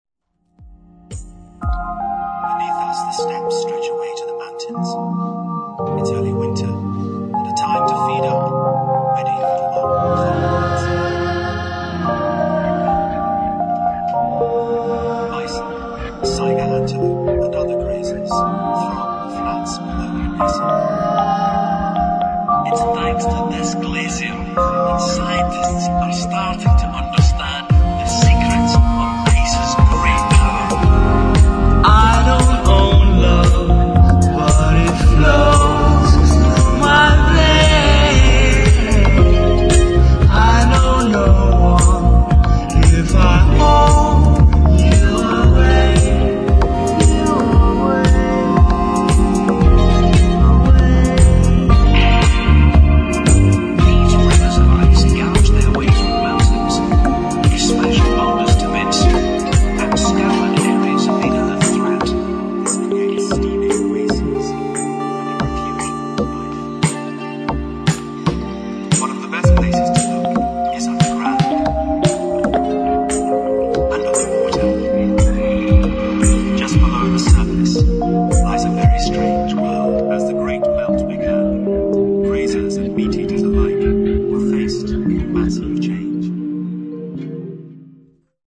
[ DEEP HOUSE / DISCO ]